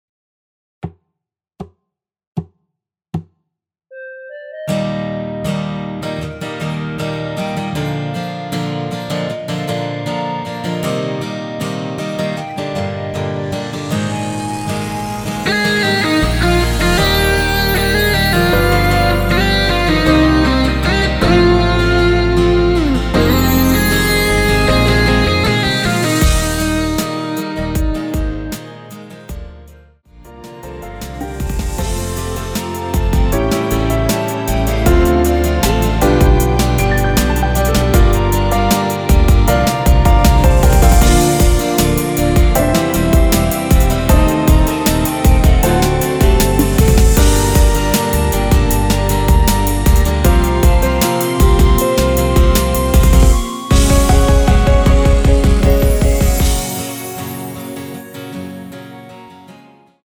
전주 없이 시작하는 곡이라서 노래하기 편하게 카운트 4박 넣었습니다.(미리듣기 확인)
원키에서(-1)내린 멜로디 포함된 MR입니다.(미리듣기 확인)
Eb
앞부분30초, 뒷부분30초씩 편집해서 올려 드리고 있습니다.
중간에 음이 끈어지고 다시 나오는 이유는